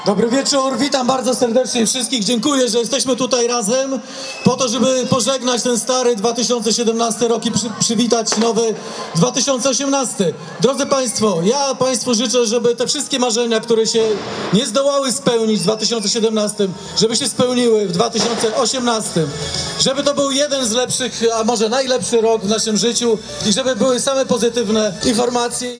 W grodzie nad Niegocinem impreza w ostatni dzień roku (31.12.) tradycyjnie odbyła się na Pasażu Portowym.
burmistrz.mp3